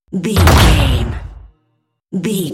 Dramatic hit door slam
Sound Effects
heavy
intense
dark
aggressive
hits